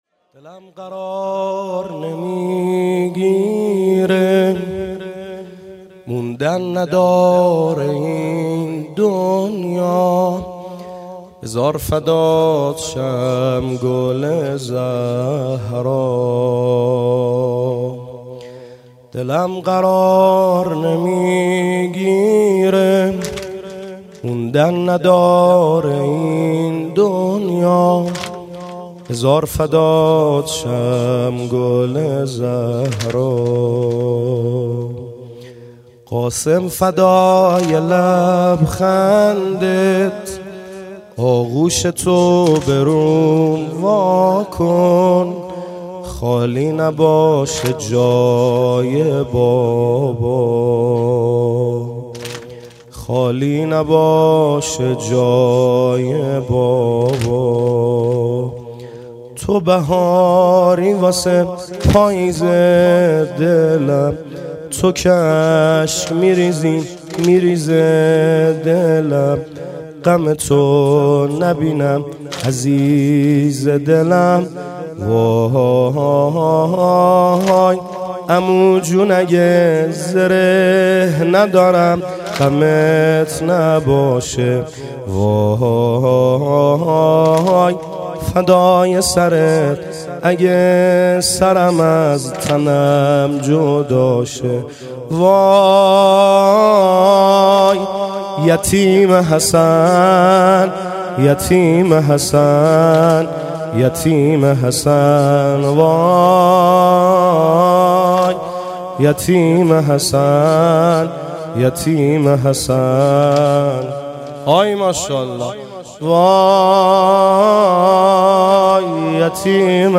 مراســم عـزادارى شـب ششم محرّم
محرم 98 - شب ششم